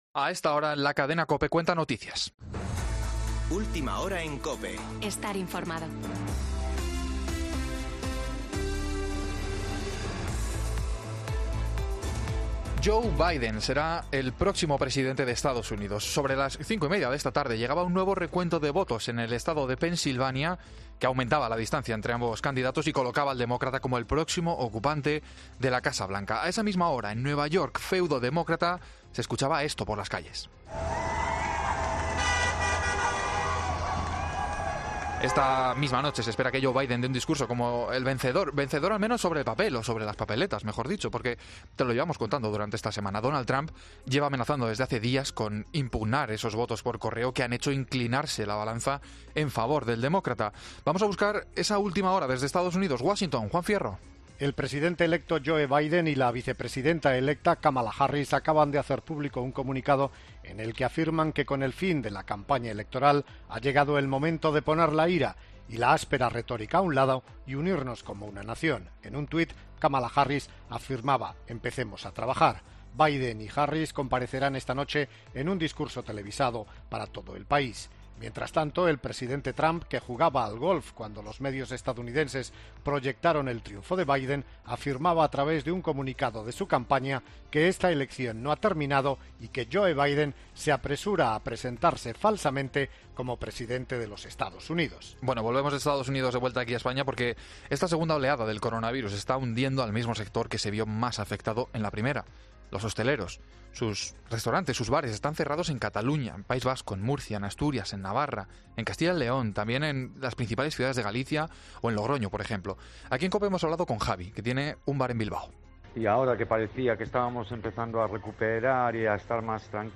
AUDIO: Boletín de noticias de COPE del 7 de noviembre de 2020 a las 19.00 horas